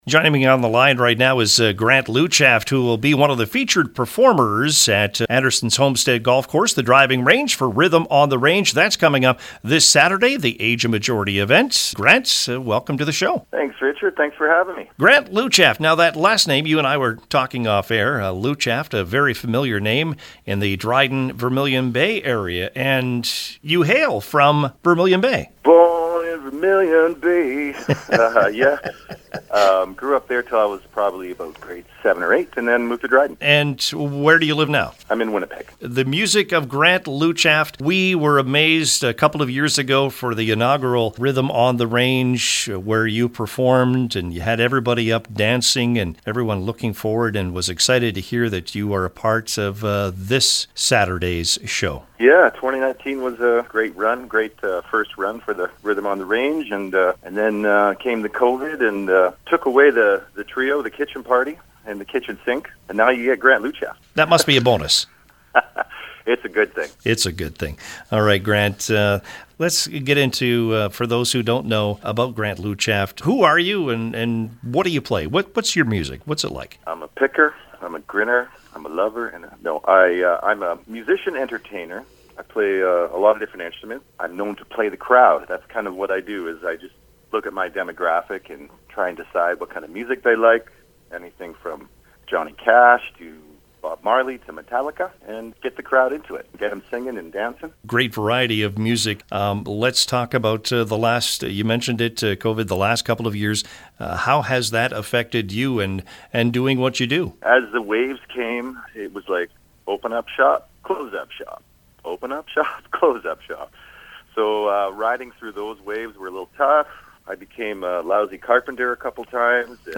One of the featured performers was a guest on the CKDR Morning Show on Monday.